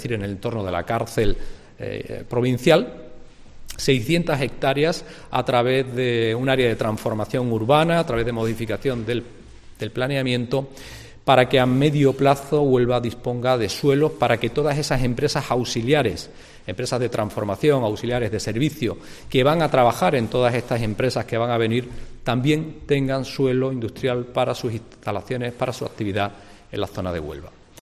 Felipe Arias, portavoz del equipo de Gobierno en el Ayuntamiento de Huelva